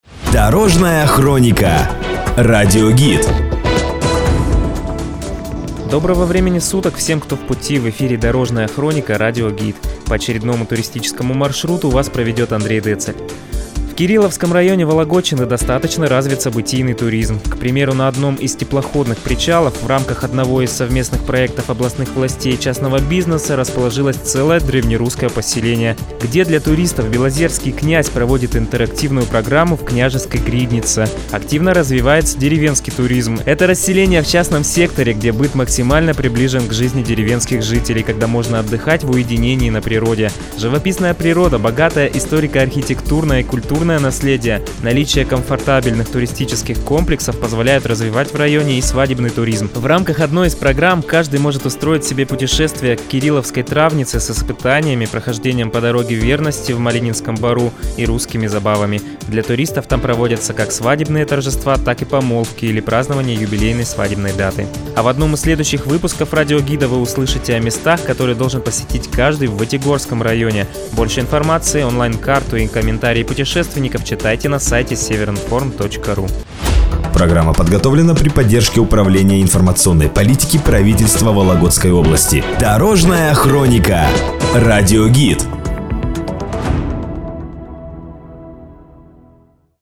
Радиогид. Событийный туризм в Кирилловском районе.